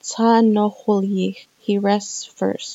Rest /nu…yix/